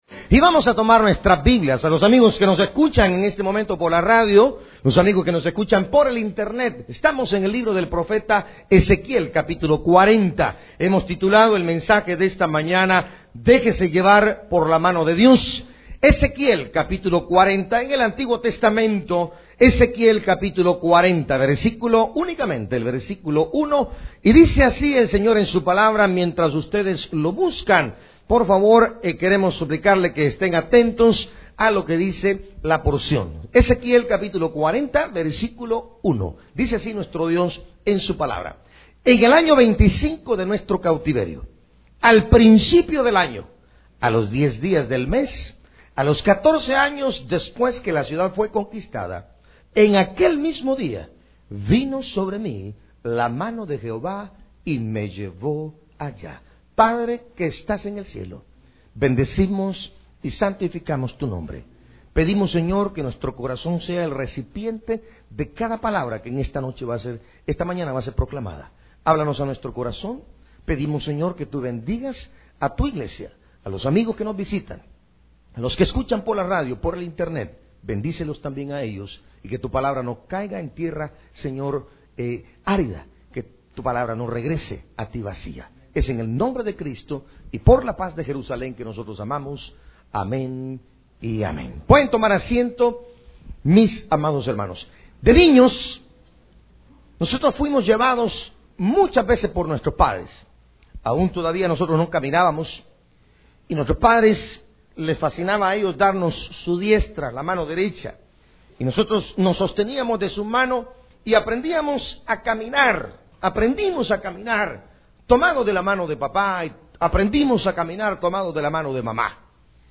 Sermones en audio y vídeo, fotografías, eventos y mucho más ¡Queremos servirle!